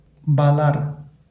wymowa:
IPA[ba.ˈlaɾ]